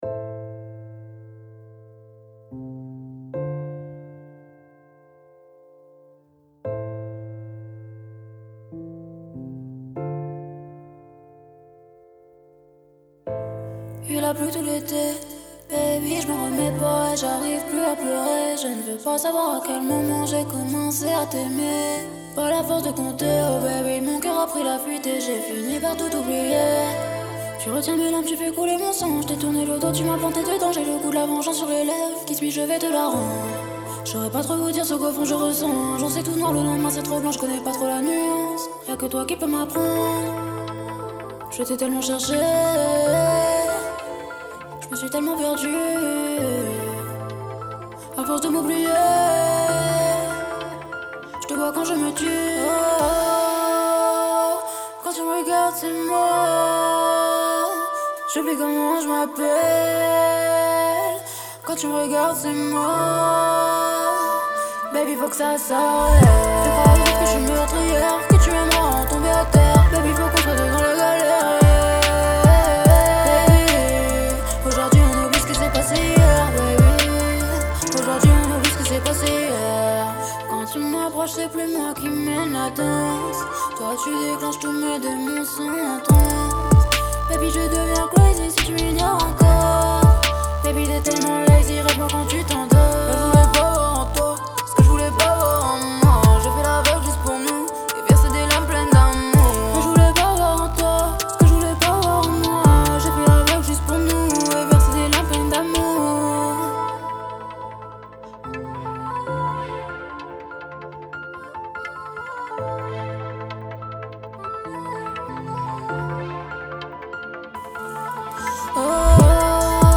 Chanteuse
15 - 27 ans - Contralto